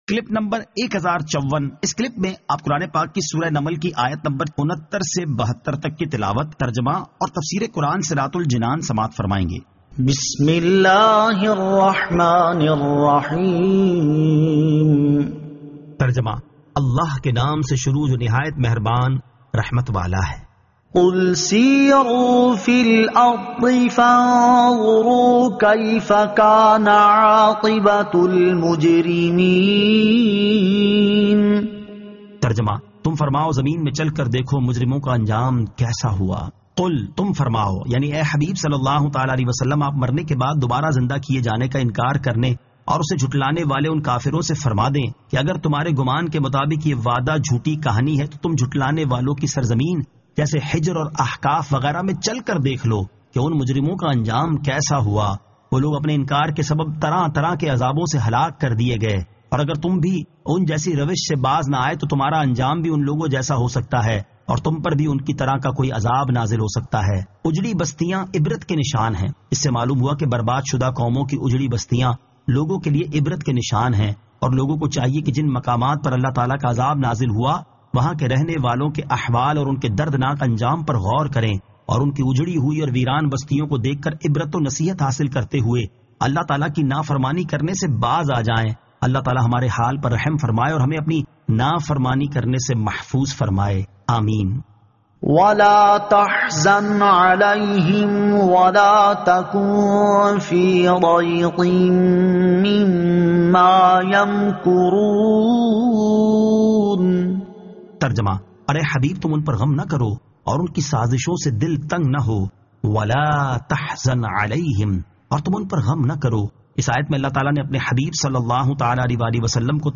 Surah An-Naml 69 To 72 Tilawat , Tarjama , Tafseer